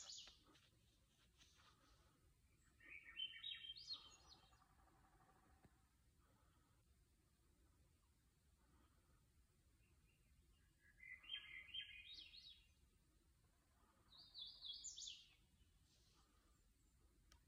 Hooded Warbler Sight Record #2024-40